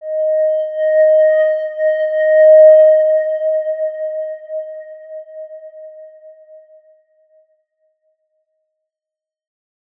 X_Windwistle-D#4-mf.wav